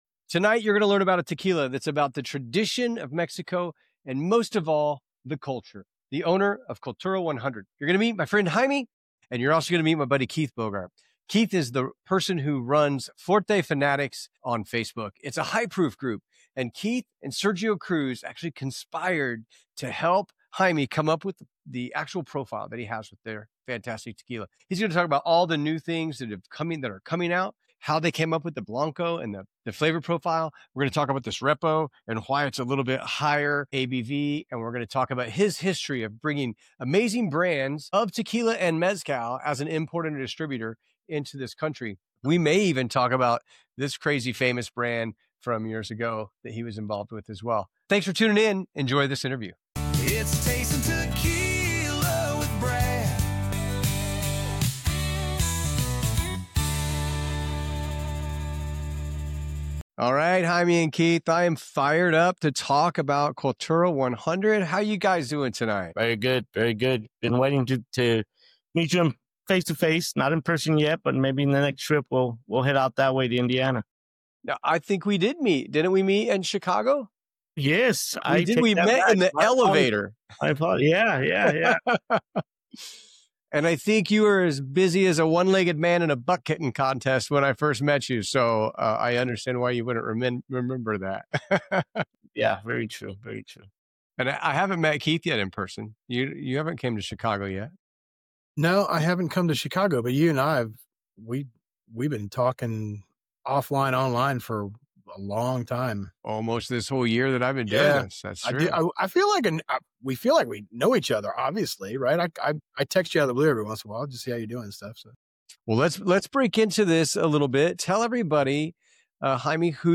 Why Cultura 100 Tequila Stands Out | Exclusive Interview